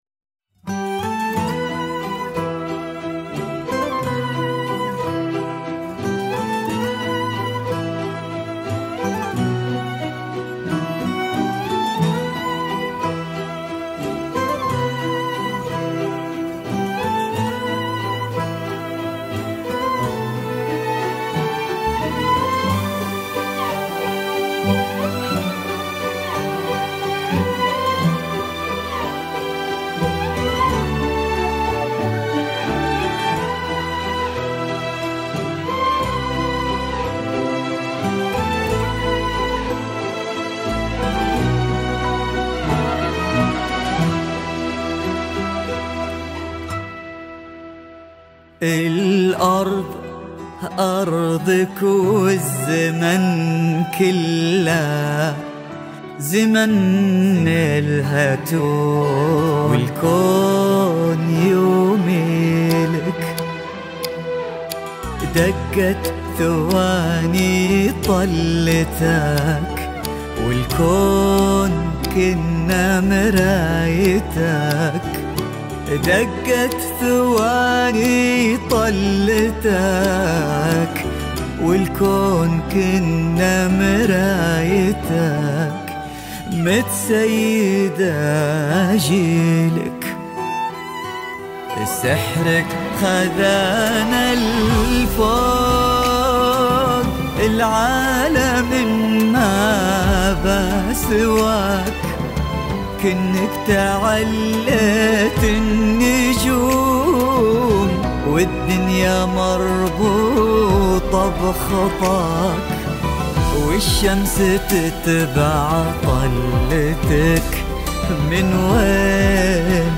زفة طله